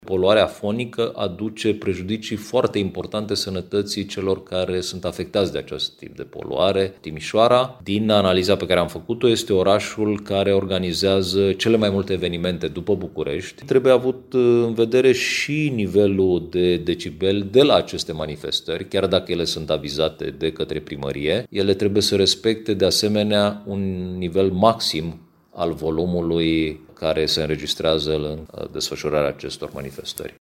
Tot în cadrul ATOP, autoritățile au stabilit un plan de măsuri pentru diminuarea poluării fonice în principal în Timișoara, mai spune subprefectul Ovidiu Drăgănescu.